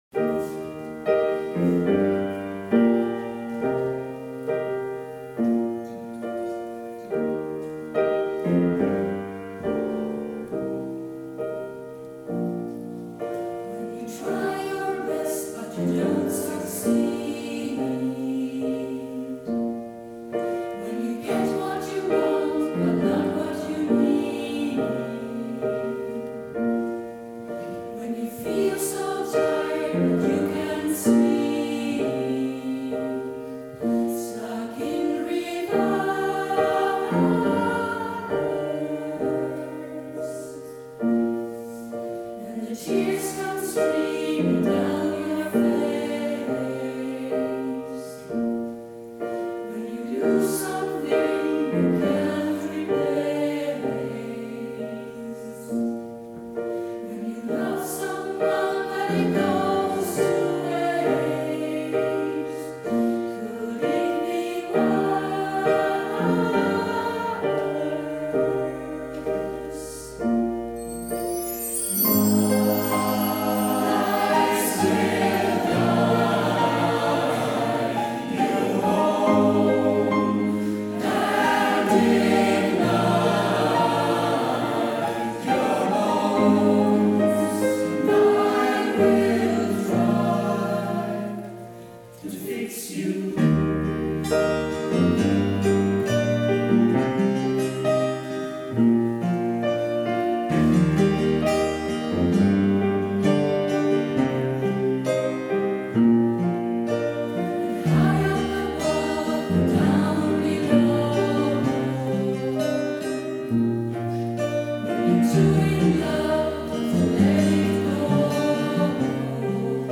Sommarkonsert 2019 Vikens kyrka